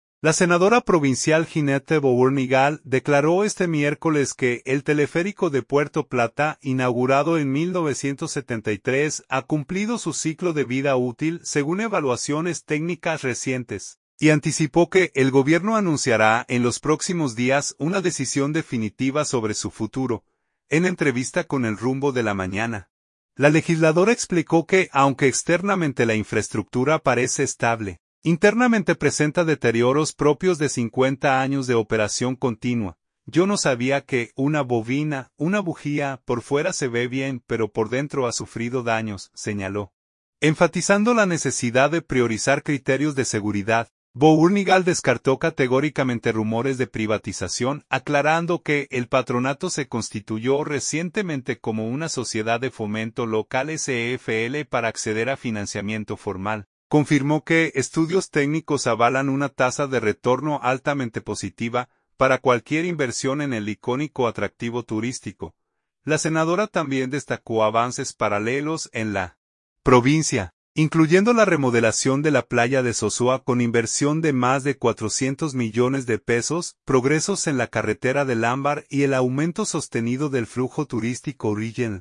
En entrevista con El Rumbo de la Mañana, la legisladora explicó que aunque externamente la infraestructura parece estable, internamente presenta deterioros propios de 50 años de operación continua.